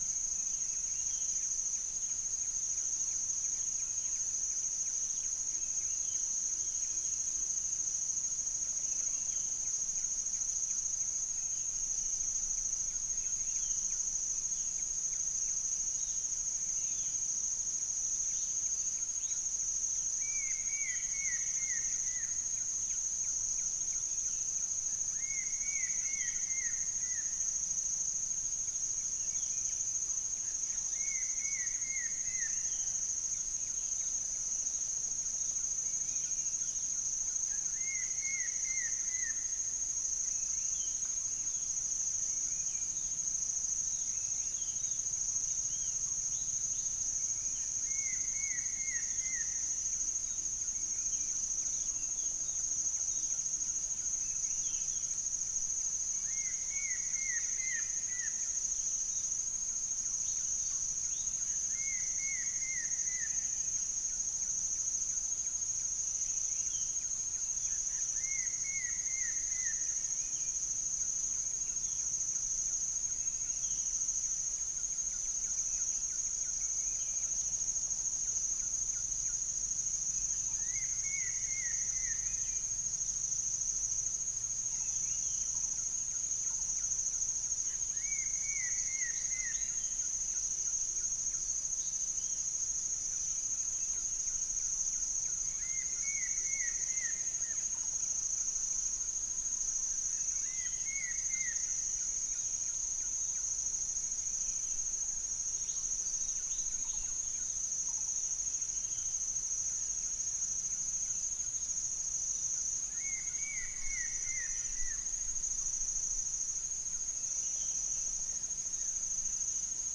SoundEFForTS Berbak NP phase 1 and 2
3475 | Pitta sordida 3461 | Centropus bengalensis 3466 | Cyanoderma erythropterum 3469 | 0 - unknown bird 3470 | Psilopogon duvaucelii